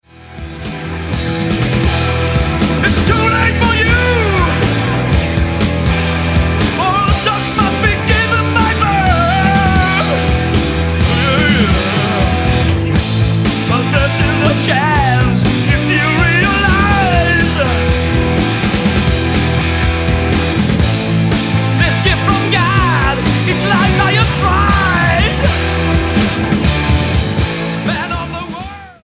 STYLE: Hard Music
twin lead guitars
interplaying with some soaring synth lines